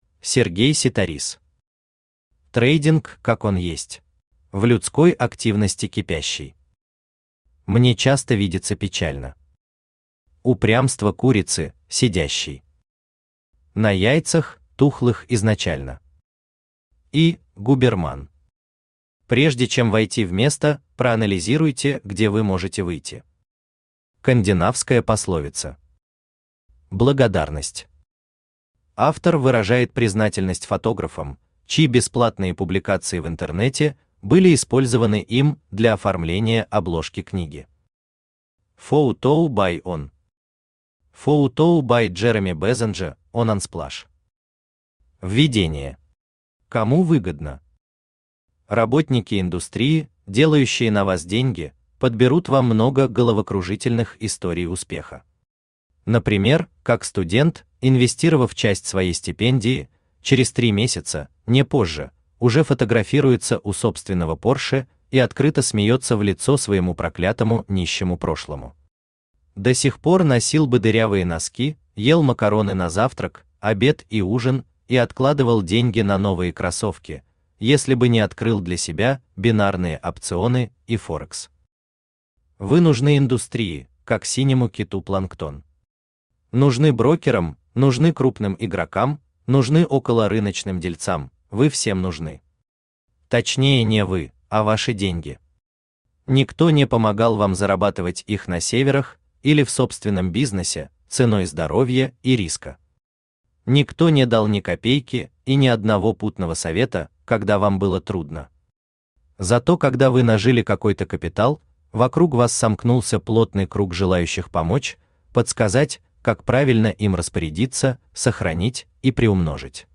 Аудиокнига Трейдинг как он есть | Библиотека аудиокниг
Aудиокнига Трейдинг как он есть Автор Сергей Ситарис Читает аудиокнигу Авточтец ЛитРес.